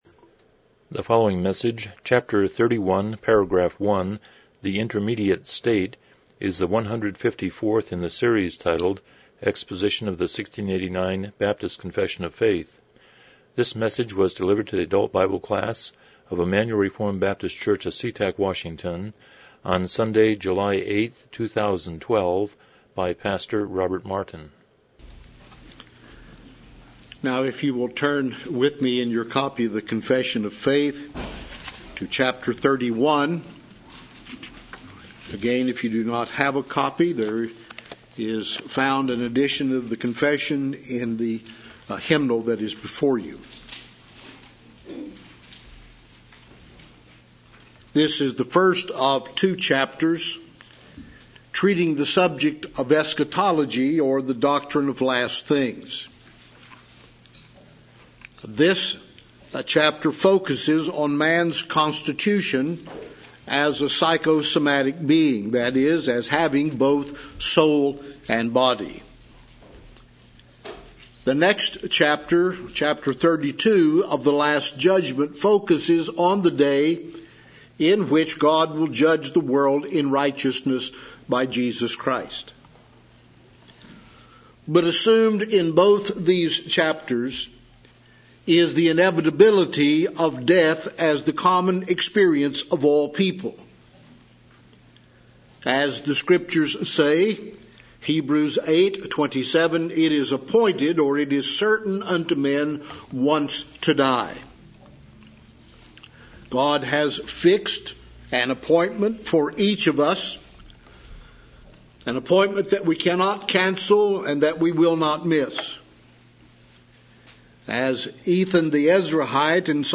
1689 Confession of Faith Service Type: Sunday School « First Love and First Works